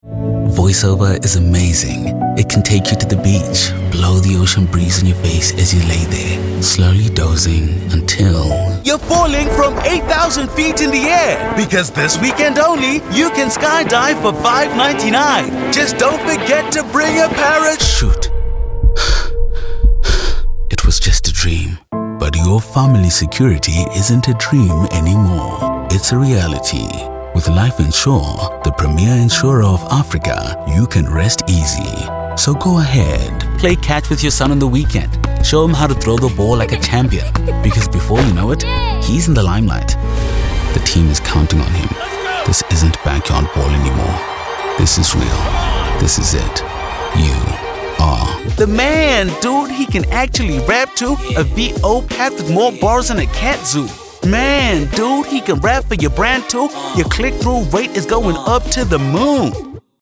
Vocal Styles:
articulate, authentic, authoritative, character, commercial, conversational, Deep, informative, inspirational, resonant
Vocal Age: